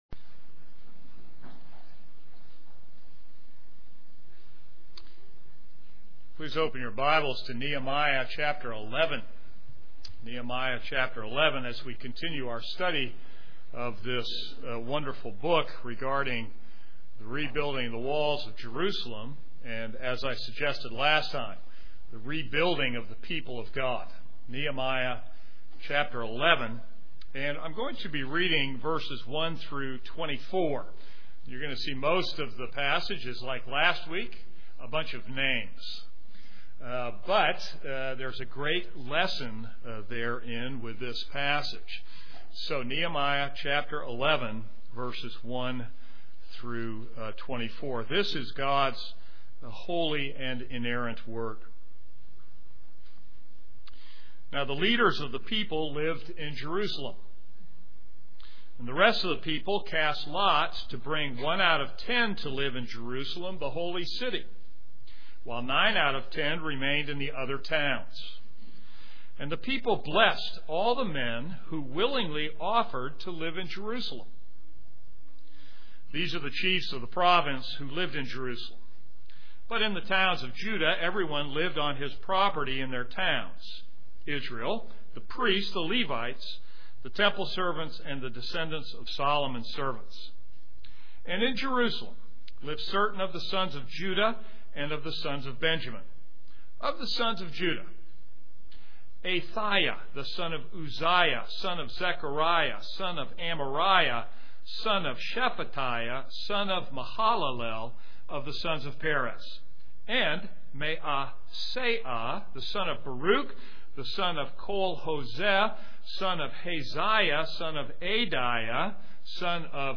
This is a sermon on Nehemiah 11:1-24.